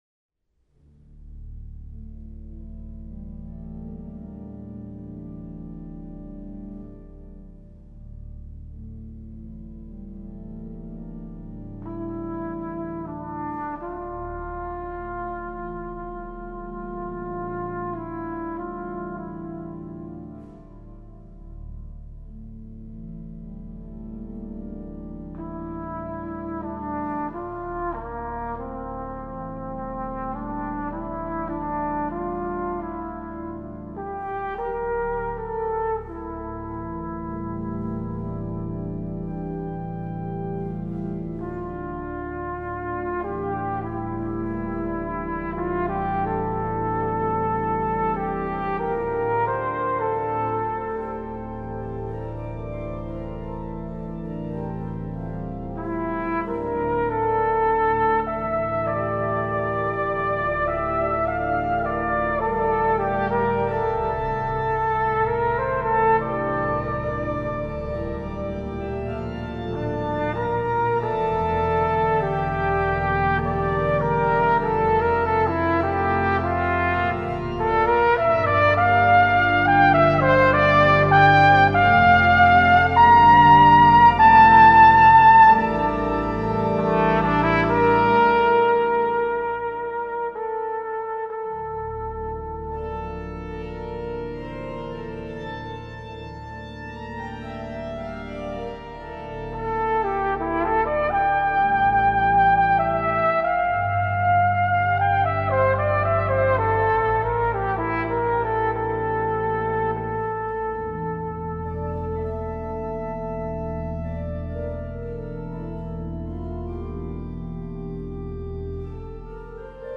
Pirmatskaņojums 01.01.2016 Rīgas Domā.